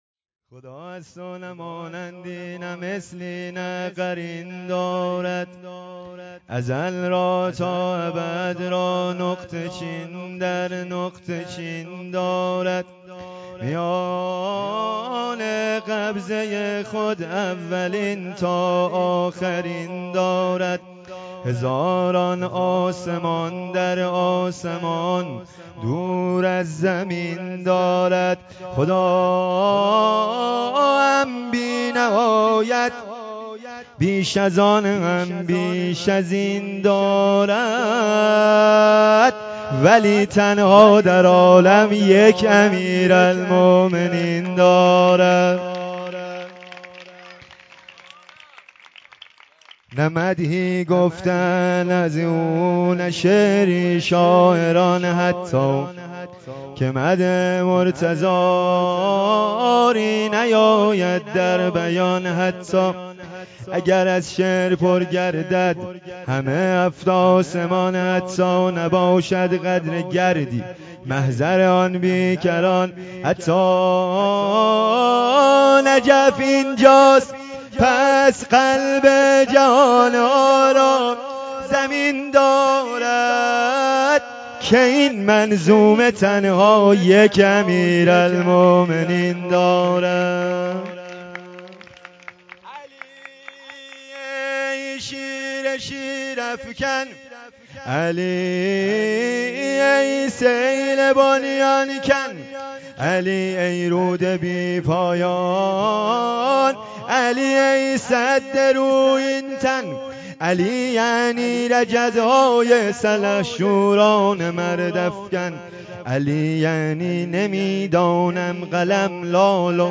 ولادت حضرت علی(ع) و ولادت حضرت جوادالائمه(ع) و ولادت حضرت علی اصغر(ع) و روز پدر 1403